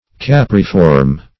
Capriform \Cap"ri*form\, a.